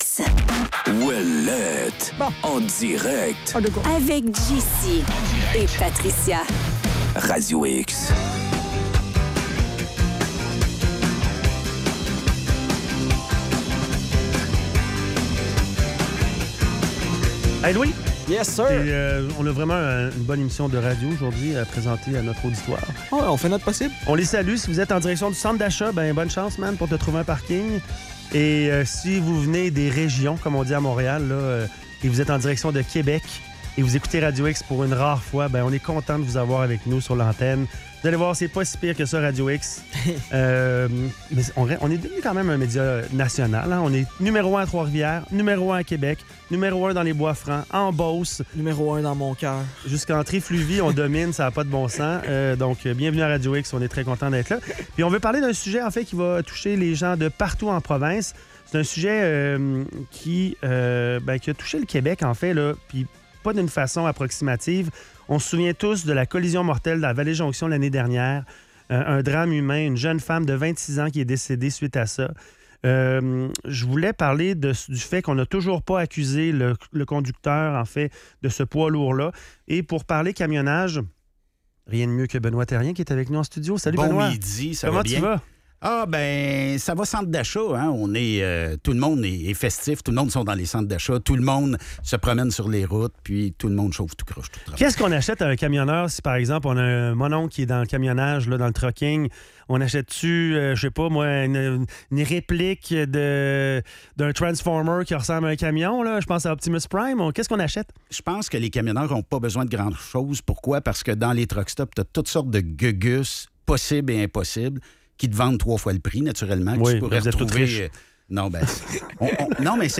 Entrevue